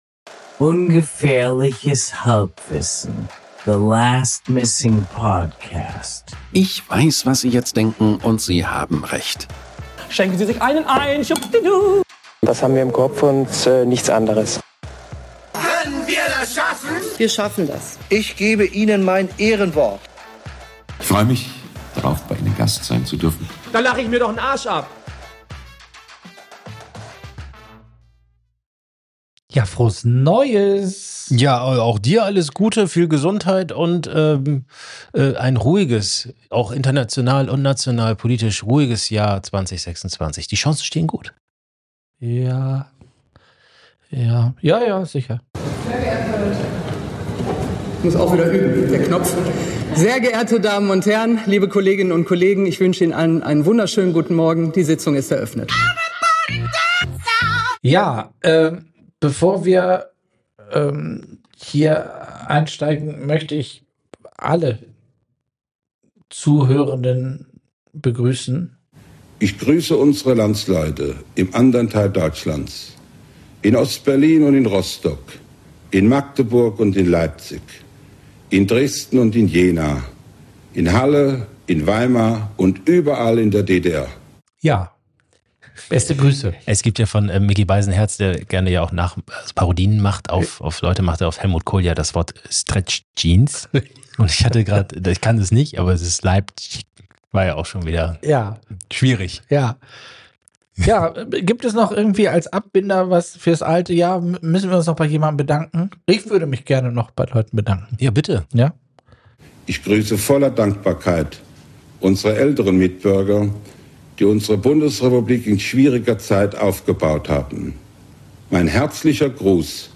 Ungefährliches Halbwissen – The Last Missing Podcast Ungefährliches Halbwissen ist der Laberpodcast de luxe, bei dem Abschweifen zur Königsdisziplin erhoben wird.
An guten Tagen ist das Hören von Ungefährliches Halbwissen wie das Belauschen eines unterhaltsamen, bisweilen anspruchsvollen, fast immer respektvollen und gelegentlich sogar informativen Gesprächs auf einer Party. Die beiden haben zwar versucht, das für sie heikle Thema Fußball zu meiden – scheitern daran aber mit schöner Regelmäßigkeit.